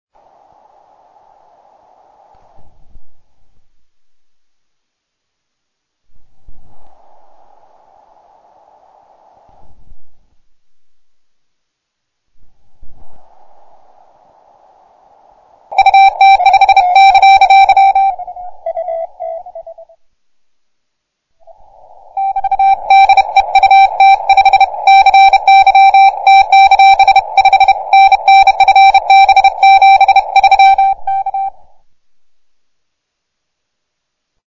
Нагрузка даже не наушники, а линейный вход Audigi-2 ZS при максимальном усилении.
В записи громкость от максимума до нуля сначала без антенны - слышен небольшой шумок NE-602.
Окрас характерный, с оттенком узкого кварцевого фильтра.
Потом воткнул антенну и тоже крутнул пару раз громкость от максимума до нуля. Уровень огромный при сигнале, но иначе картину шумов не покажешь.
PFR-3A_noise.mp3